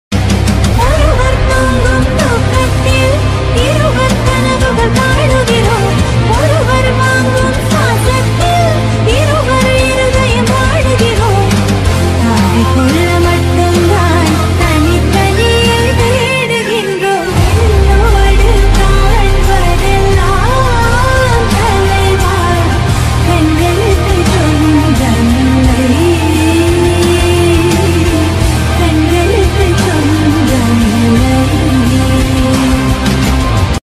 Tamil song